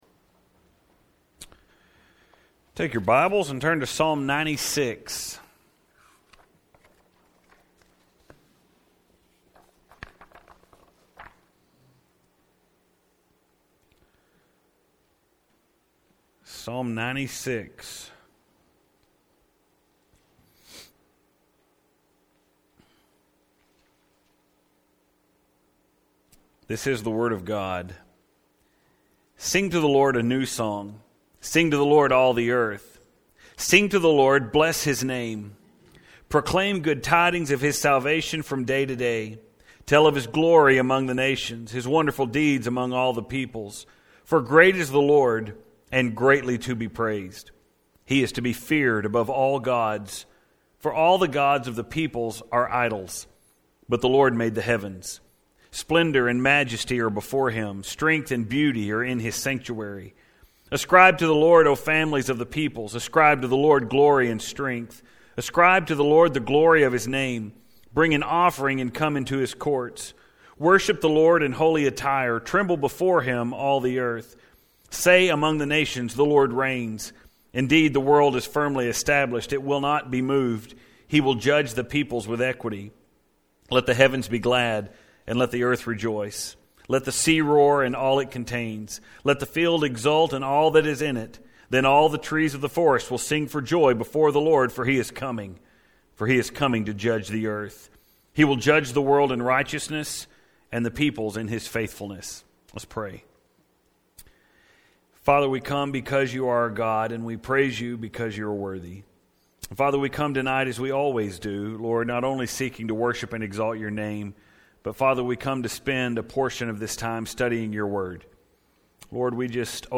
Tonight is the final service we are having in what is traditionally our “Missions Month” here at church.